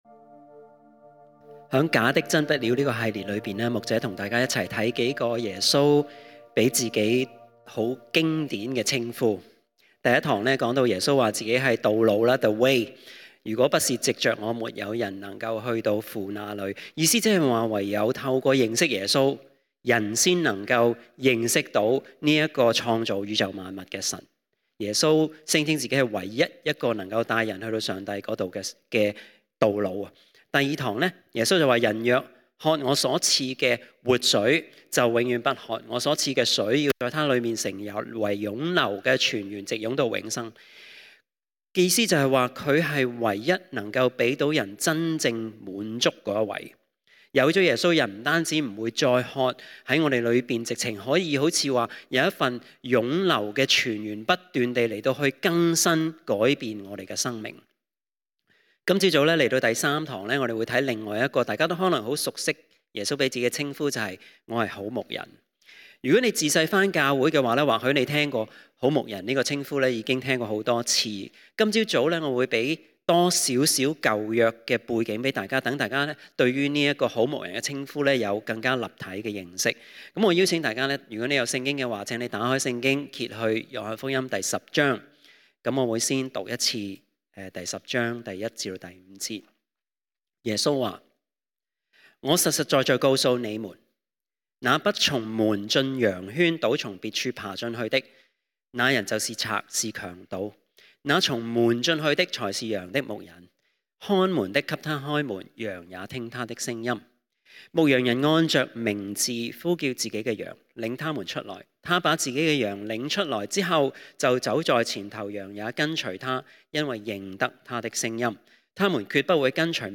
這系列的講道看到耶穌給自己的稱呼： 第一堂：耶穌是唯一能夠帶我們找到上帝的道路，我們唯有透過認識耶穌，才可以認識創造宇宙萬物的神。